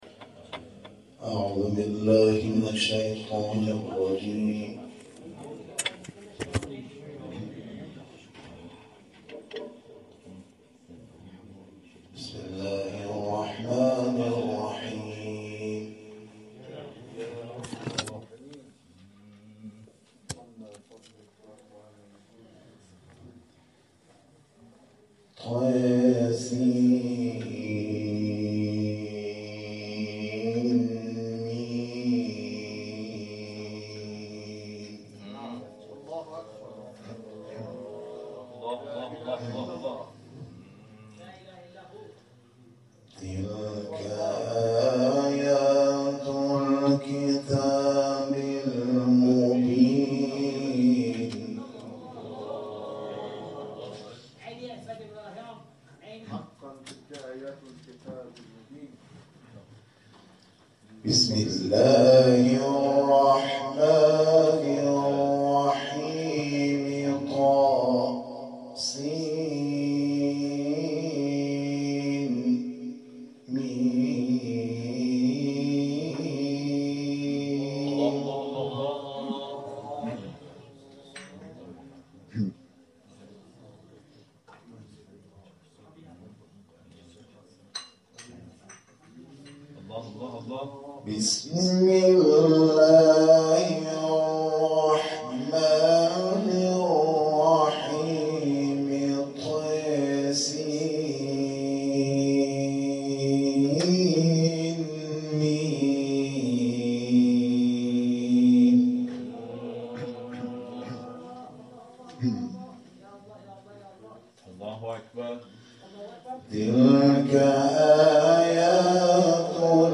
تلاوت آیاتی از سوره قصص